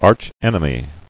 [arch·en·e·my]